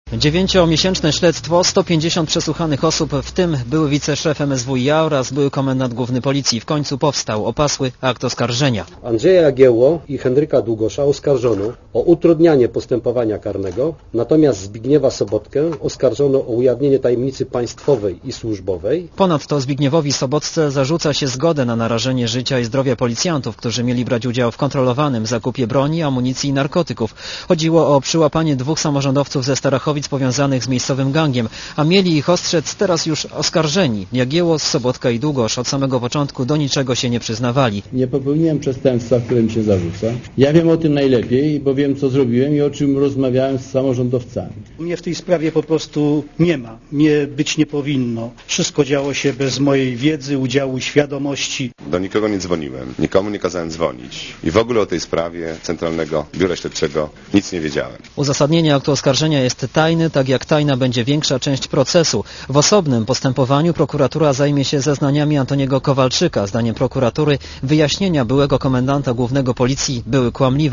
Relacja reportera Radia Zet